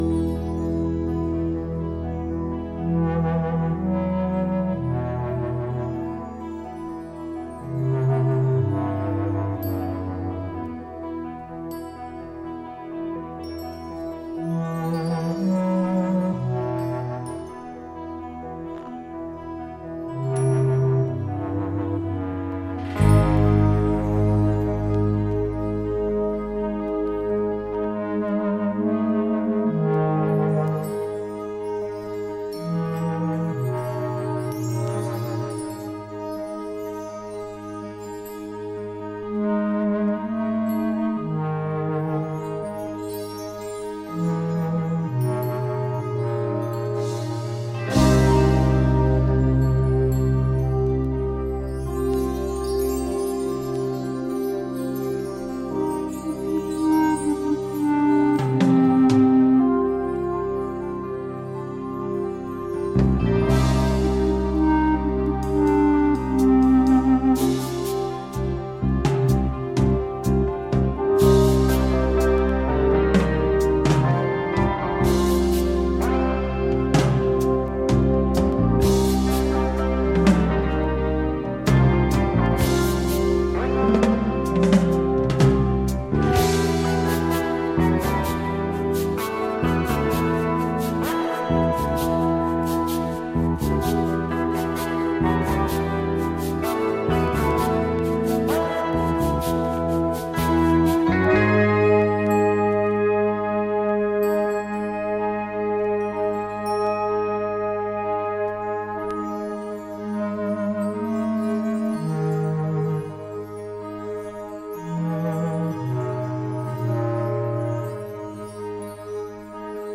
ambient, spacey, pastoral and electronic.